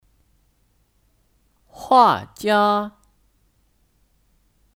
画家 (Huàjiā 画家)